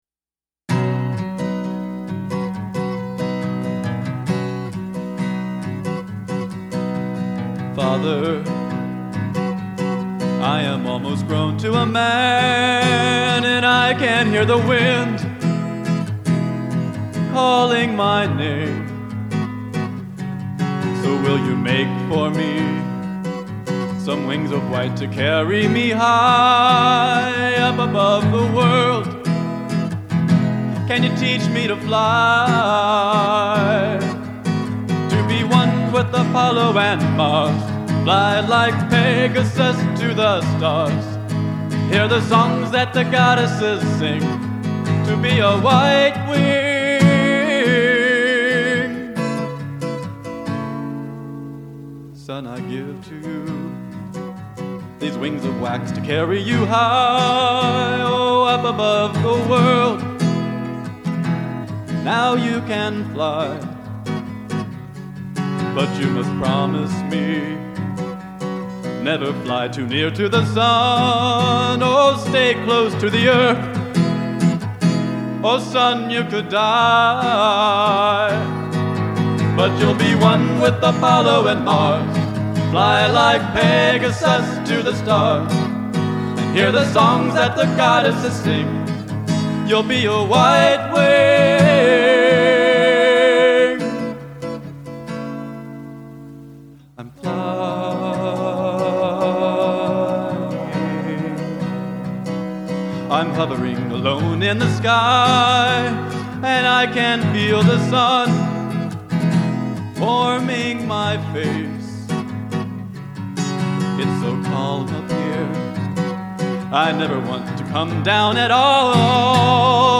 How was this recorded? a 10-song cassette demo on a 4-track reel-to-reel system